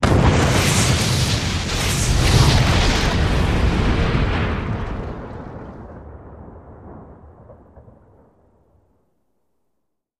Explosions; Multiple 01